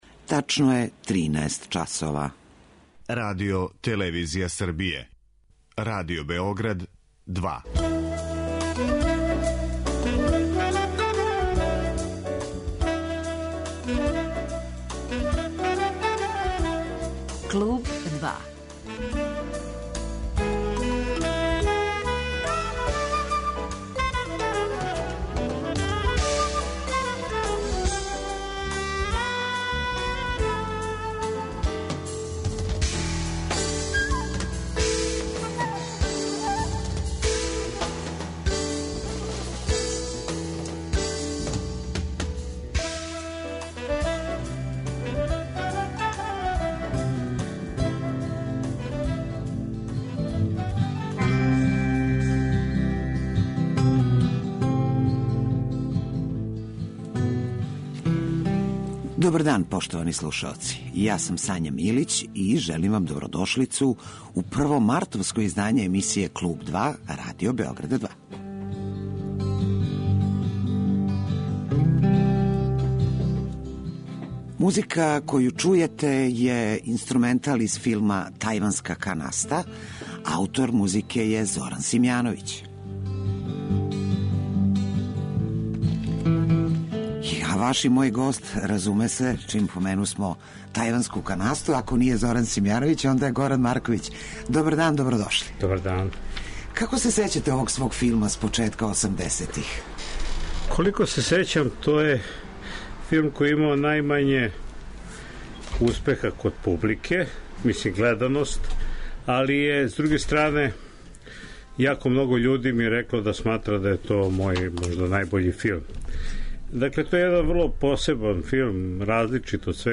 Гост емисије биће Горан Марковић, редитељ и писац. Разговараћемо о прожимању филмске, позоришне и књижевне уметности у његовом опусу.